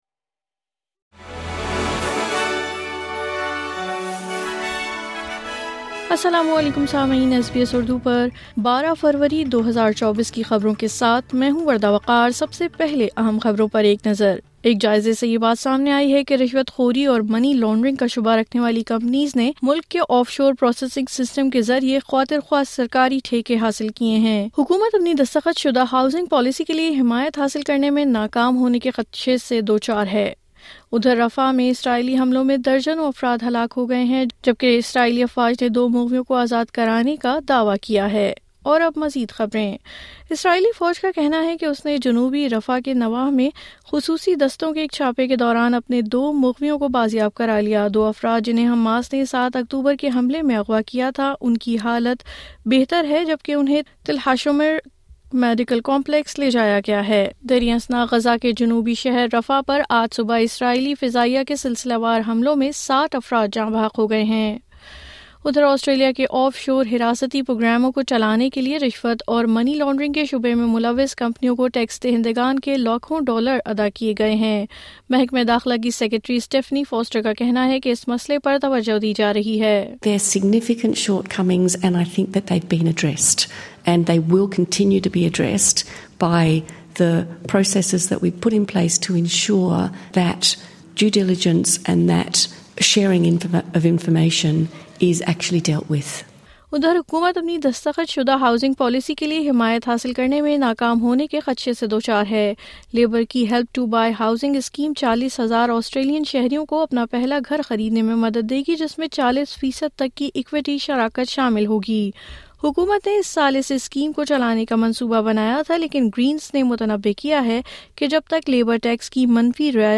نیوز فلیش:12 فروری 2024:حکومت کی دستخط شدہ ہاوسنگ پالیسی کھٹائی میں پڑ سکتی ہے